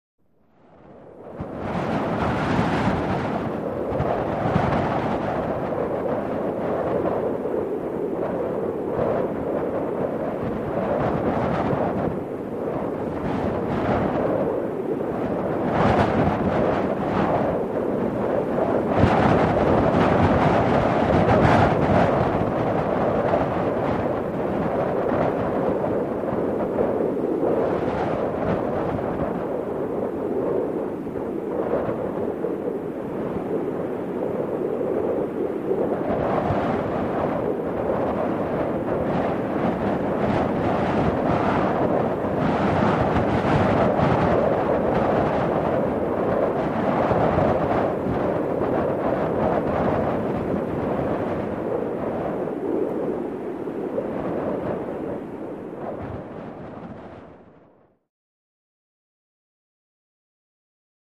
HeavyWindGustingHo PE033501
Heavy Wind; Gusting And Howling.